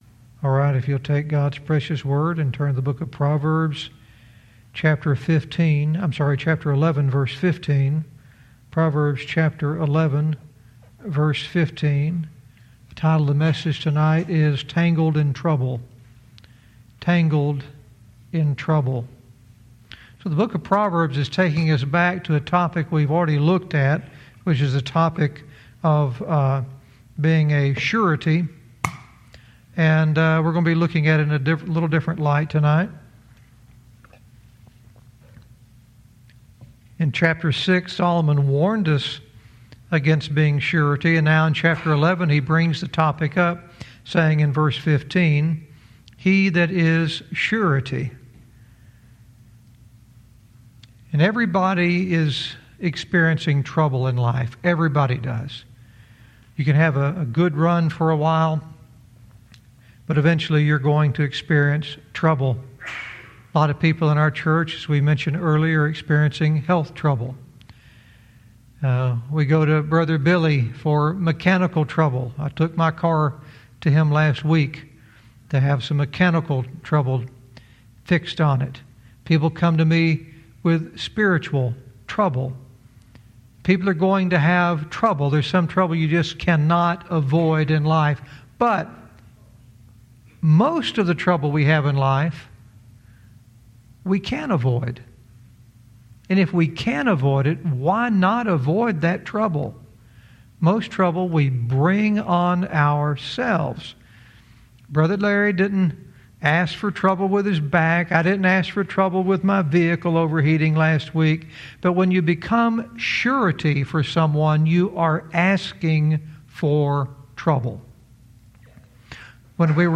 Verse by verse teaching - Proverbs 11:15 "Tangled in Trouble"